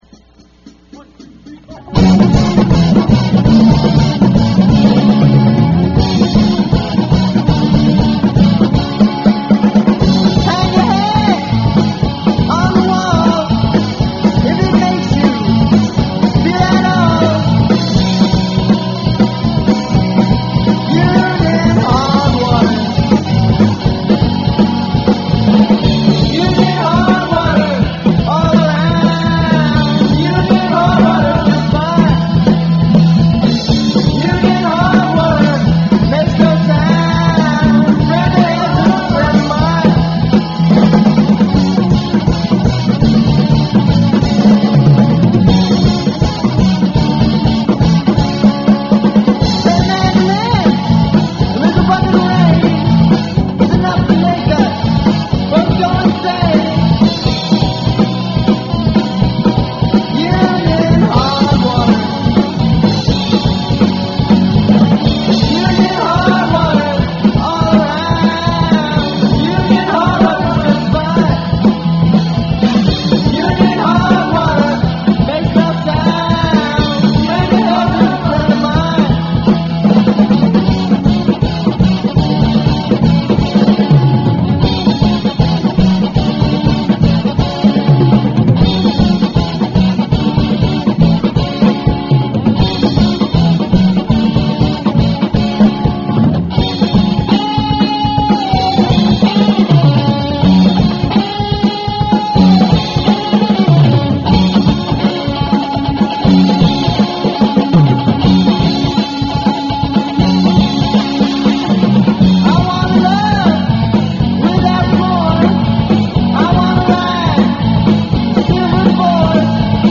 Vocals
STUDIO RECORDINGS